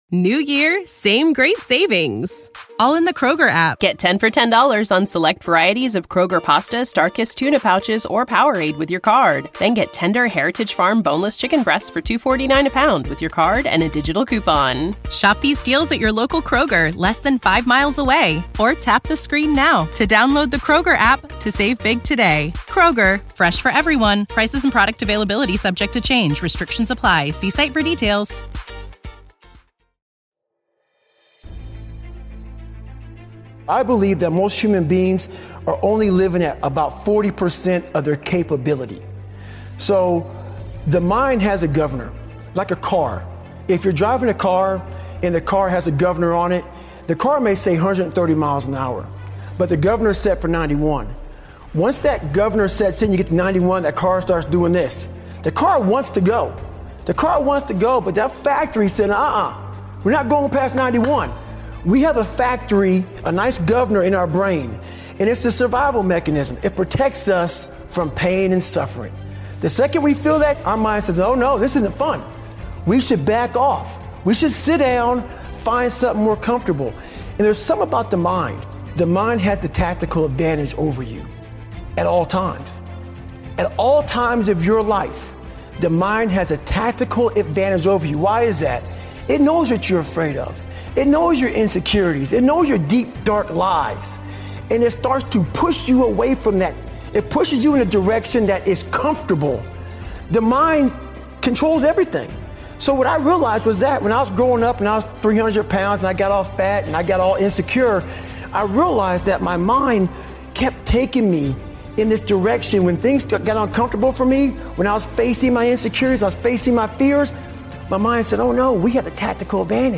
THE 40 PERCENT RULE - Powerful Motivational Speech